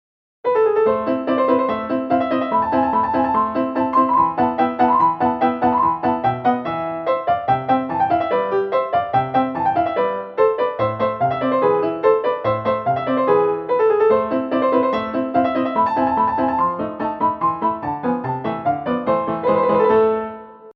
ぜんぜん和の楽曲ではありません。